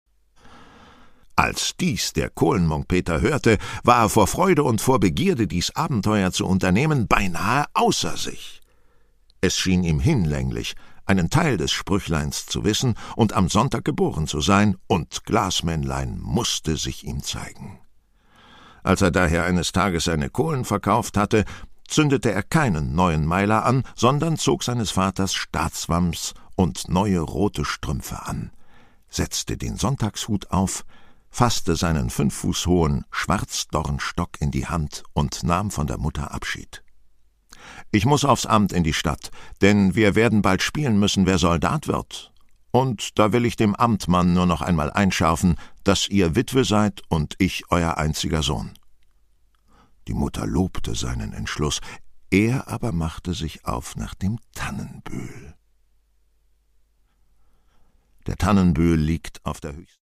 Produkttyp: Hörbuch-Download
Gelesen von: Christian Rode, Angela Winkler